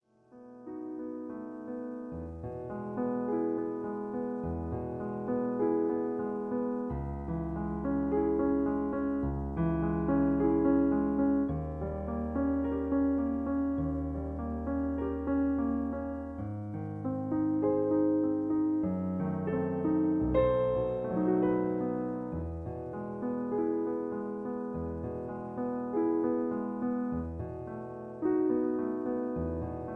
Original Key (E). Piano Accompaniment